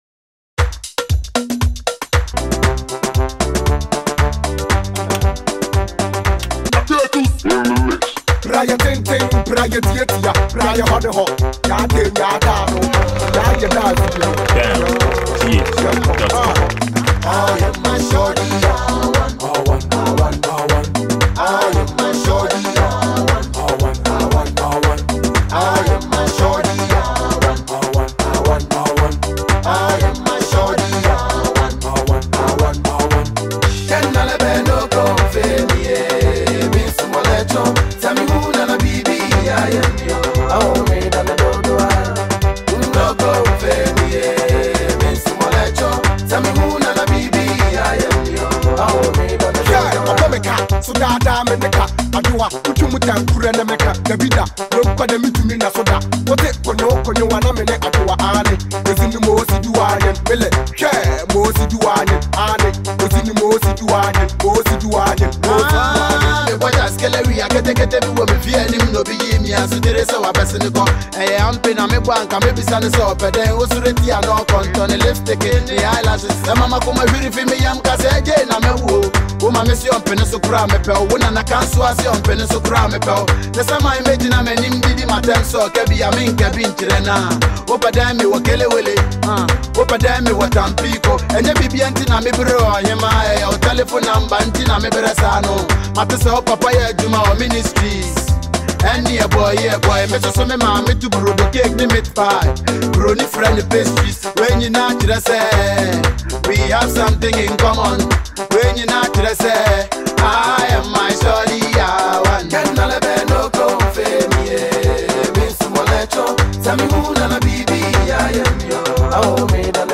Ghanaian trio musicians
a throwback free Ghana hiplife mp3.